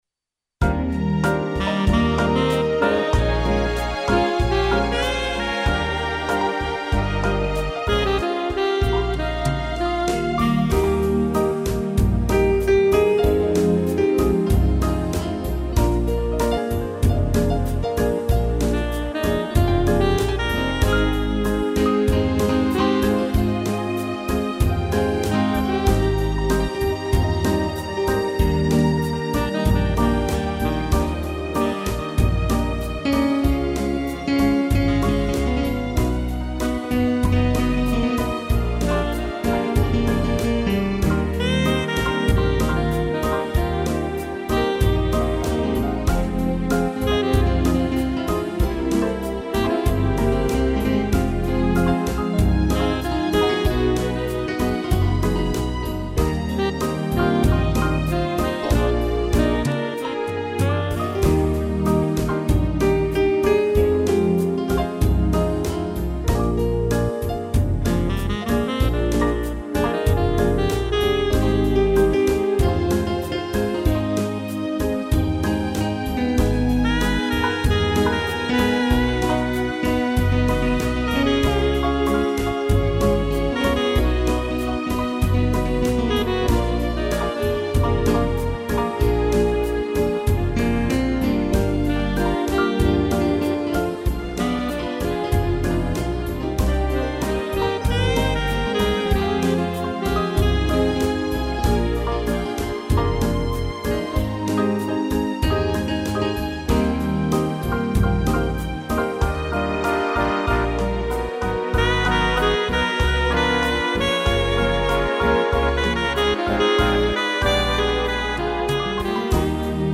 piano, strings e sax
instrumental